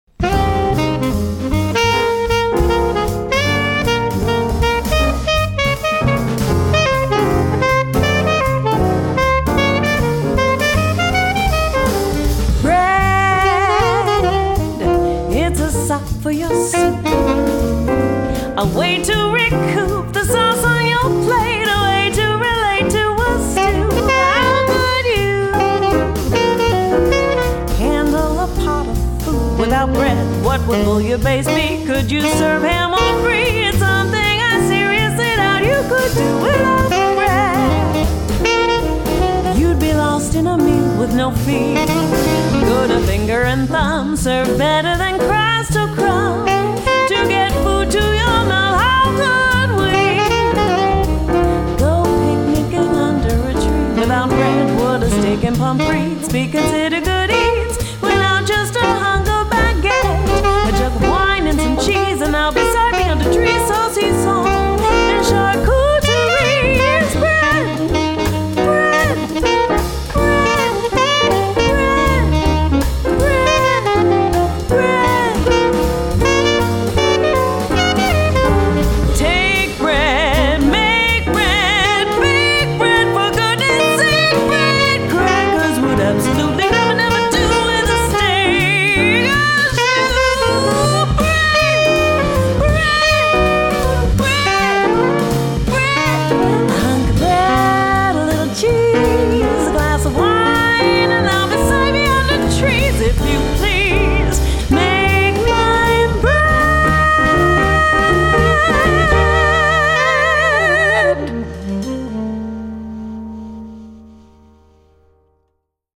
vocals
tenor sax